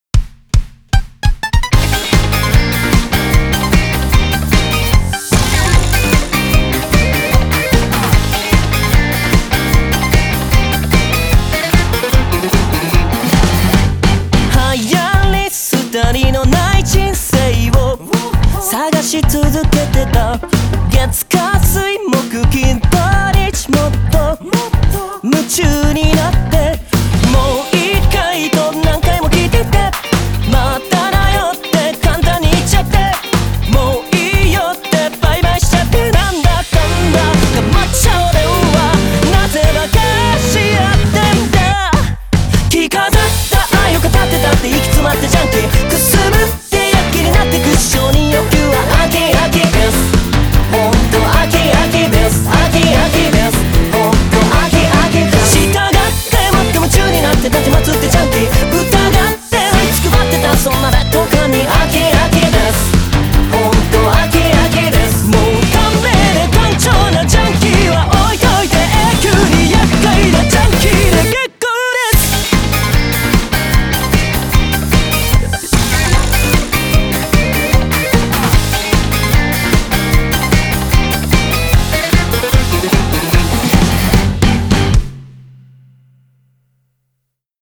BPM150
MP3 QualityMusic Cut